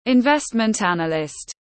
Investment analyst /ɪnˈvest.mənt ˈæn.ə.lɪst /